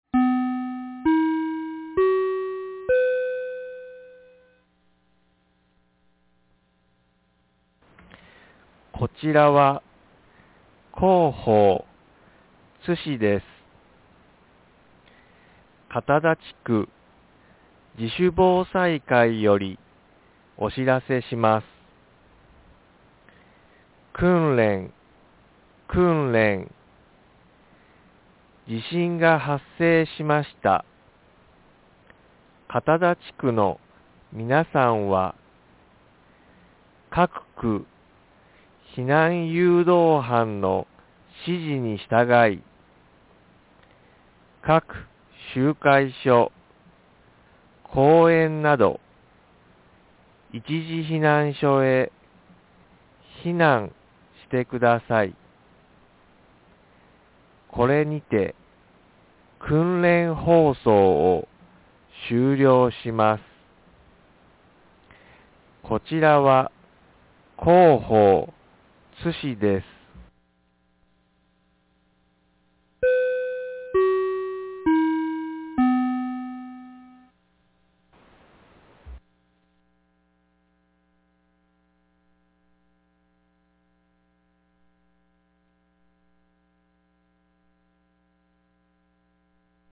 2024年11月30日 07時49分に、津市より片田へ放送がありました。
放送音声